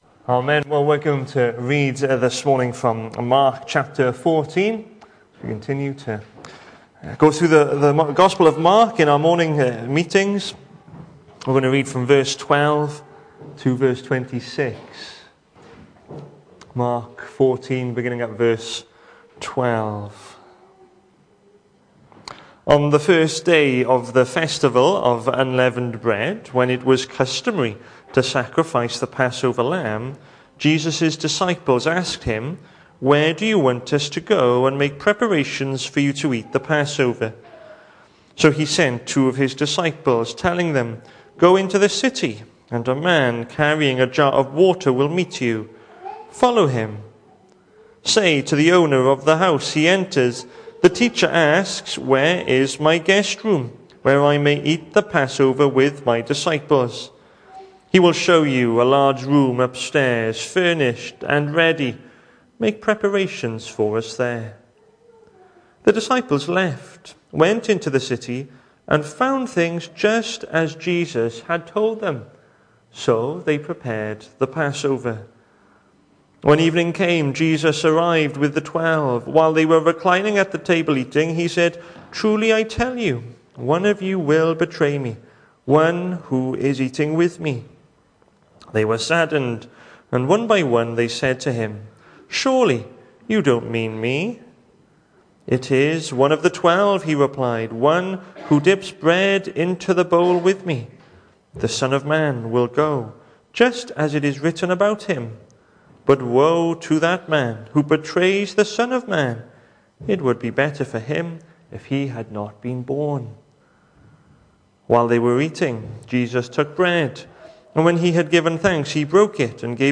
The 2nd of November saw us host our Sunday morning service from the church building, with a livestream available via Facebook.